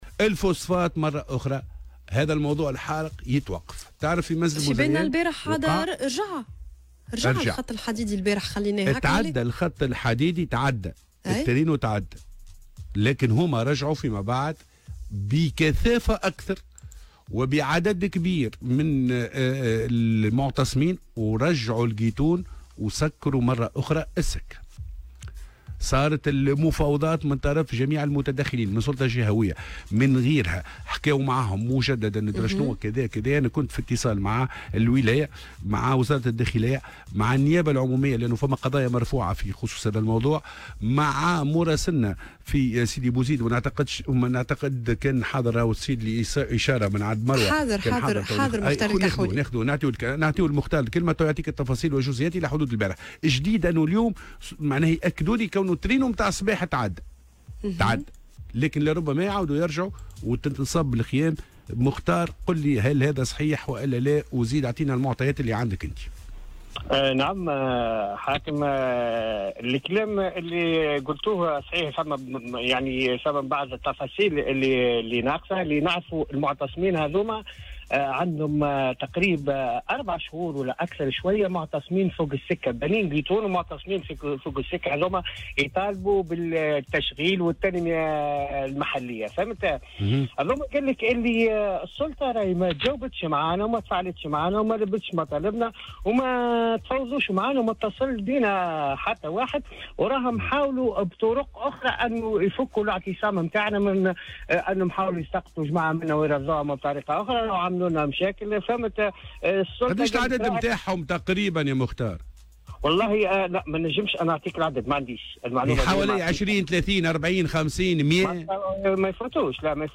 وأضاف في مداخلة له اليوم في برنامج "صباح الورد" أن المنطقة تشهد هدوء حذرا وذلك بعد مواجهات اندلعت أمس بين قوات الأمن وعدد من المحتجين المعتصمين على مستوى السكة الحديدية رقم 13 الرابطة بين قفصة وصفاقس عبر مدينة منزل بوزيان وذلك للمطالبة بالتنمية والتشغيل.